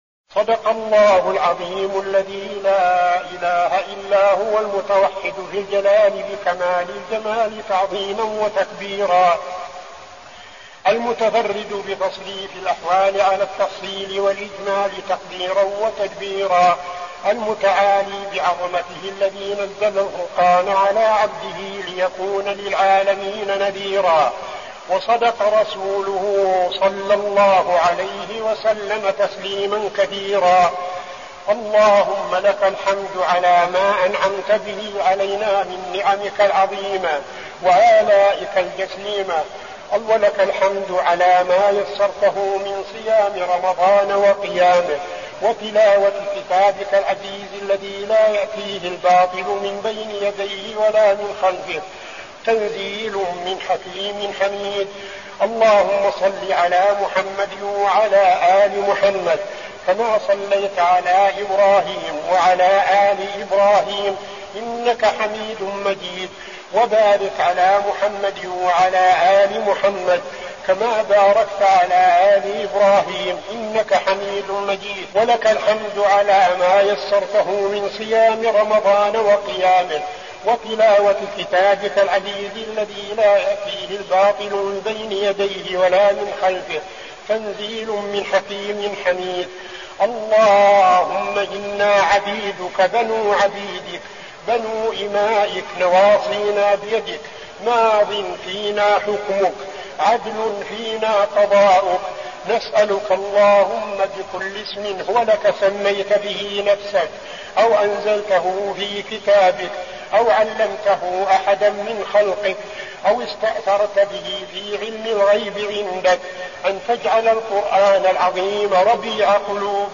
الدعاء
المكان: المسجد النبوي الشيخ: فضيلة الشيخ عبدالعزيز بن صالح فضيلة الشيخ عبدالعزيز بن صالح الدعاء